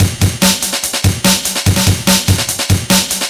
cw_amen11_145.wav